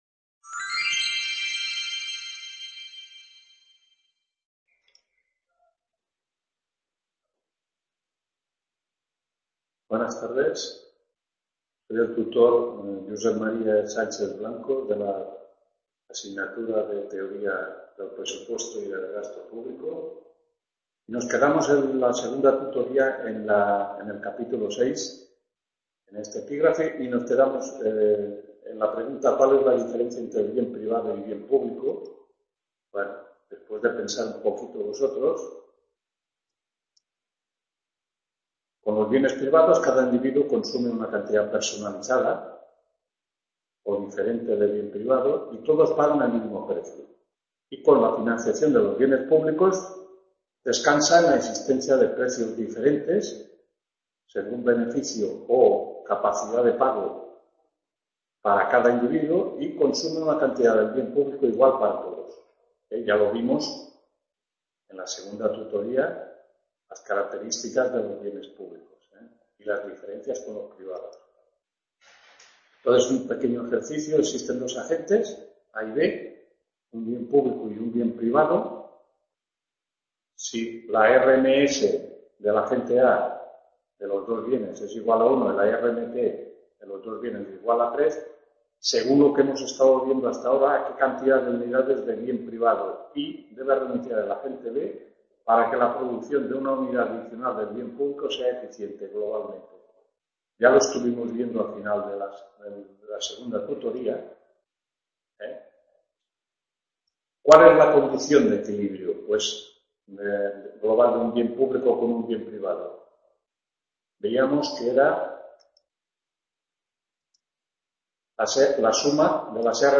3ª TUTORÍA TEORÍA DEL PRESUPUESTO Y DEL GASTO PÚBLICO…